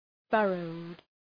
Shkrimi fonetik{‘fɜ:rəʋd, ‘fʌrəʋd}